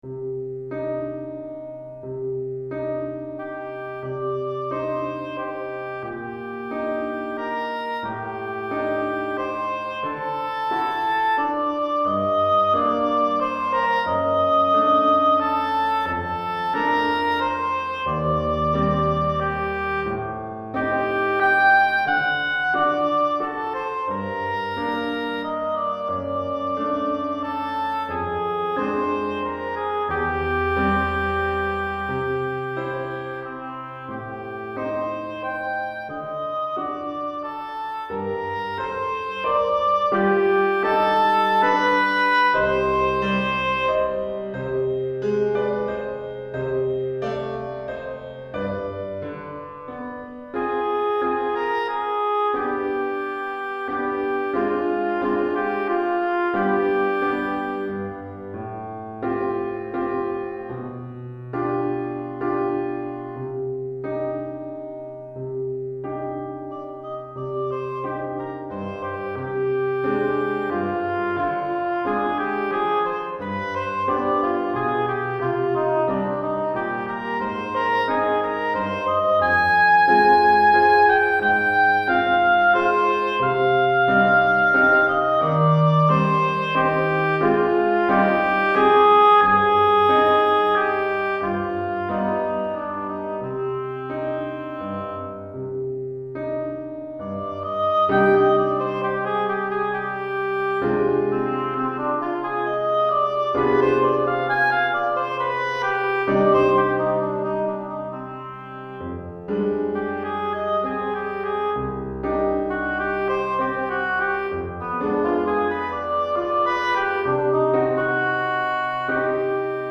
Pour hautbois et piano DEGRE fin de cycle 1 Durée